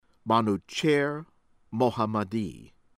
Mohammad Mokhber mo hah MAD eh / mock BAIR